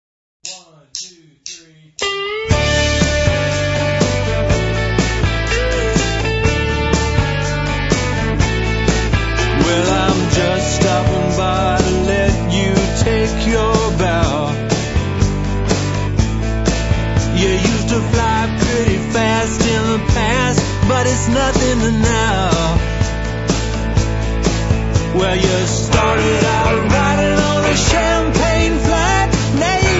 • country music
• registrazione sonora di musica